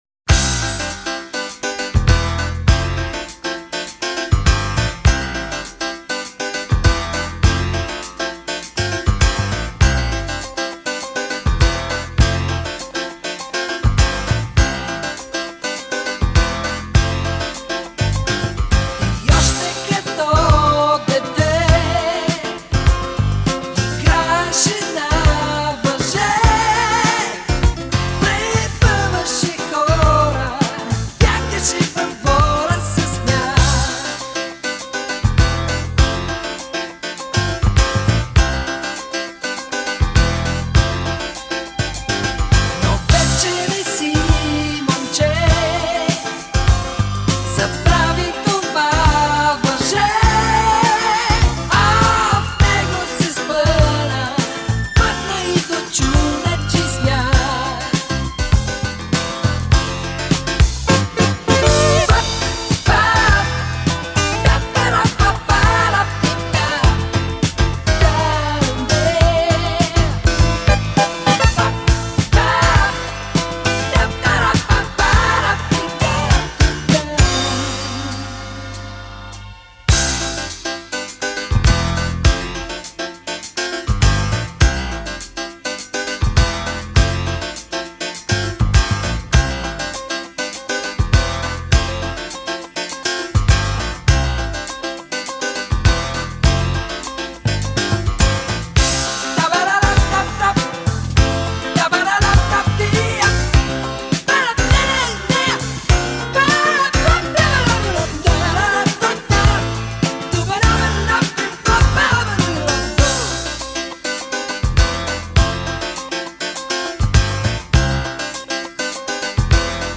Есть и рок-композиции и джаз-рок. Есть диско-композиции.